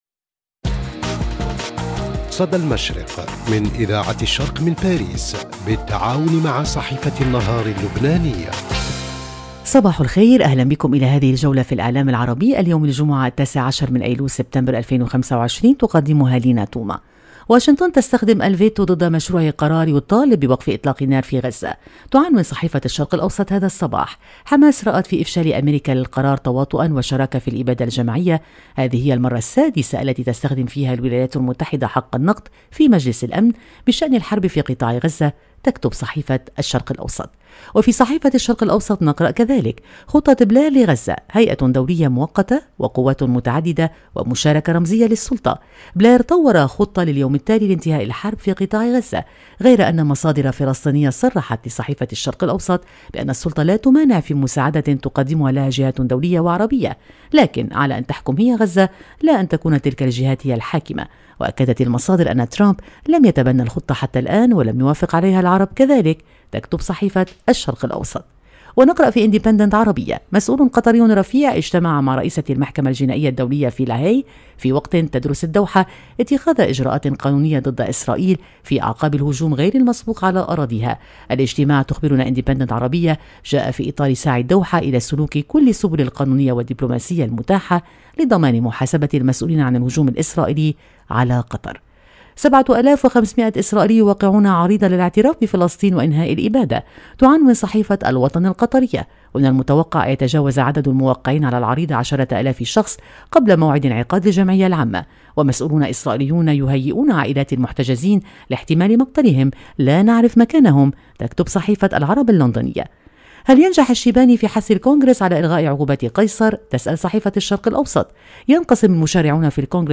إعداد وتقديم